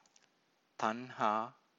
Thaṇhā